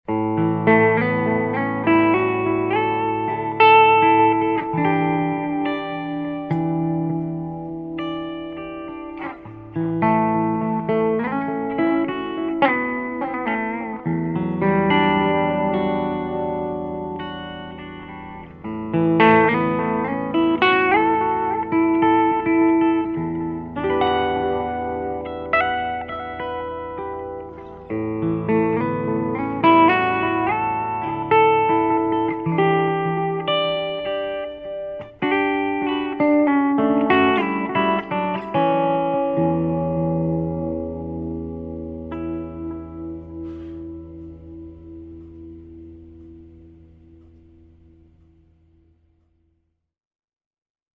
AMAZE0 for ARDX20 モジュレーション・デモ音源
AMAZE0_for_ARDX20_Modulation_Demo2.mp3